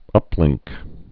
(ŭplĭngk)